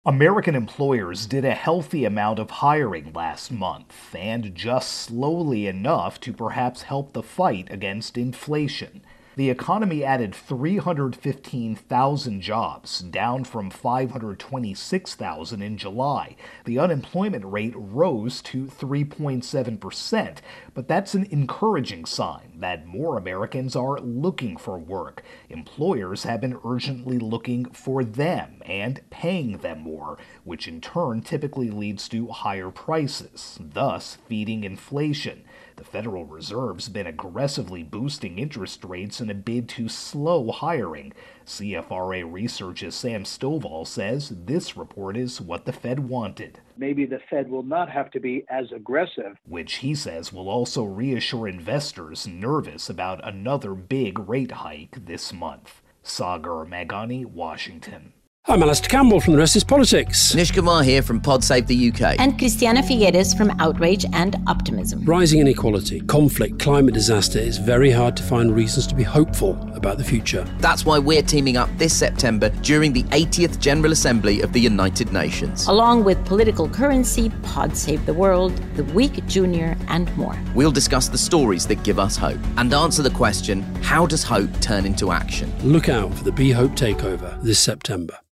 Latest Stories from The Associated Press